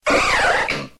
Cri de Colossinge dans Pokémon X et Y.